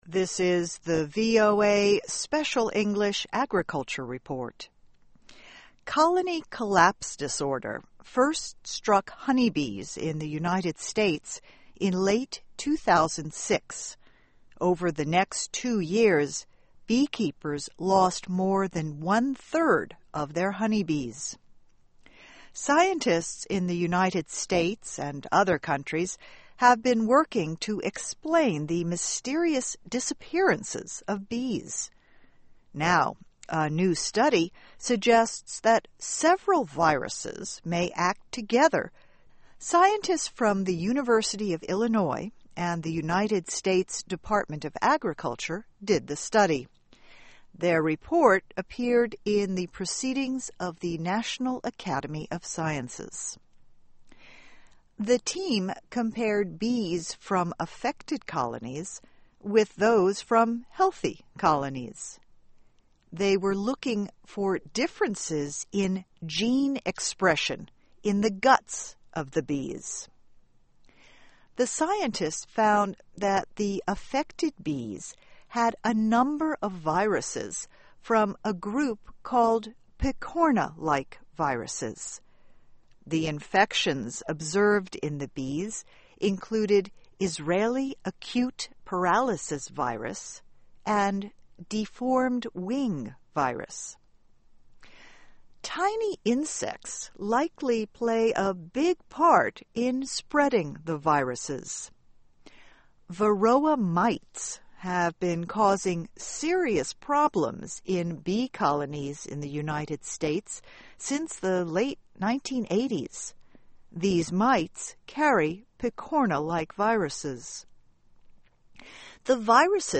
A number of viruses acting together may help explain cases of colony collapse. Transcript of radio broadcast.